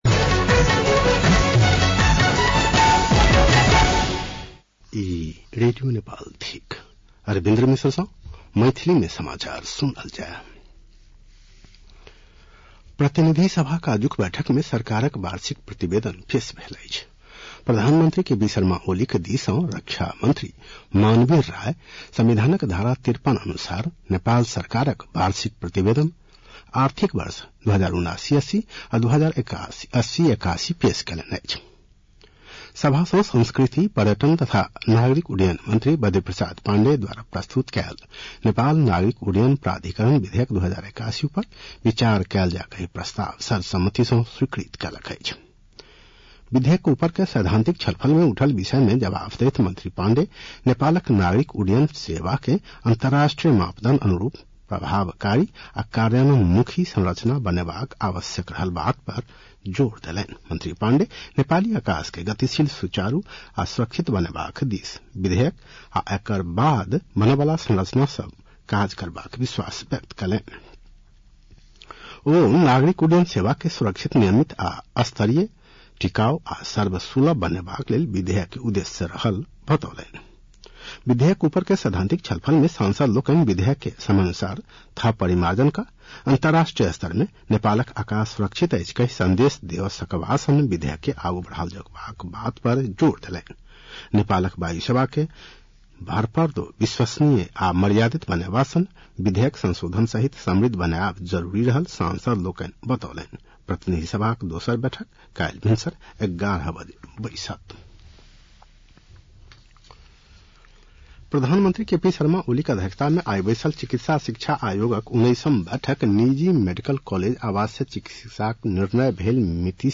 मैथिली भाषामा समाचार : १५ वैशाख , २०८२
Maithali-news-1-15.mp3